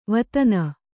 ♪ vatana